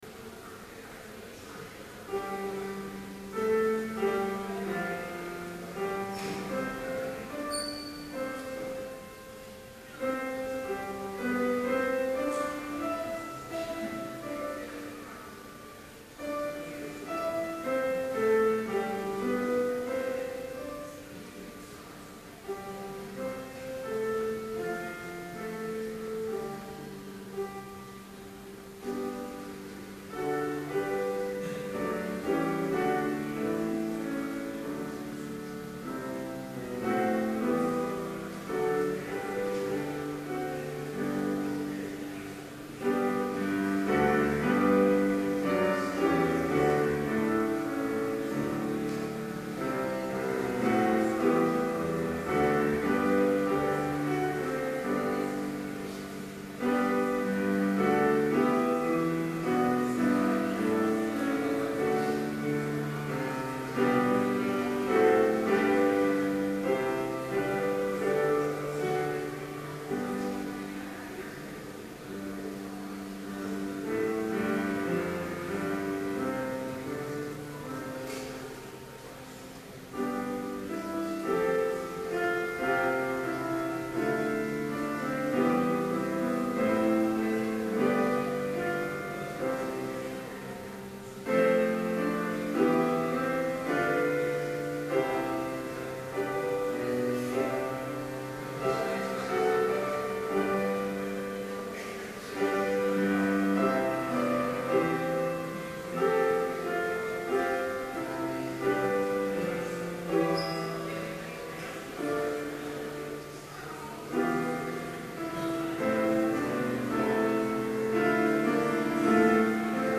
Complete service audio for Chapel - May 7, 2012